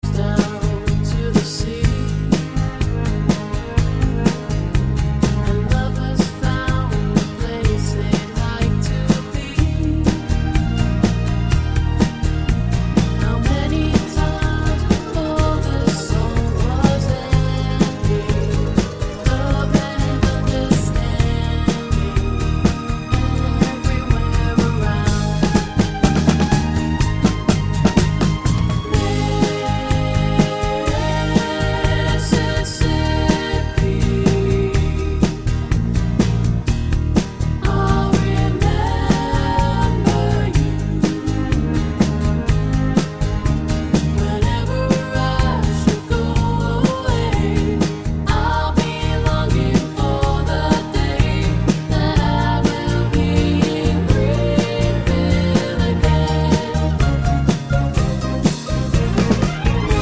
Down 2 Semitones Pop (1970s) 4:34 Buy £1.50